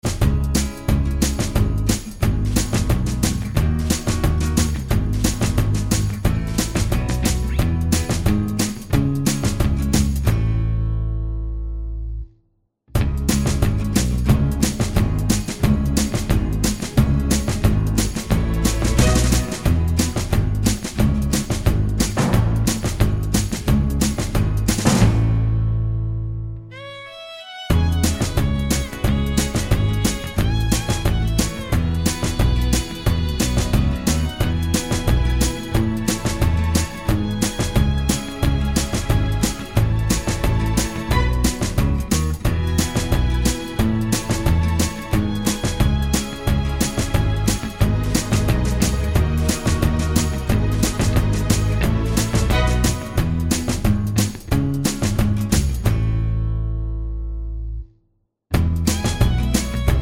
Medleys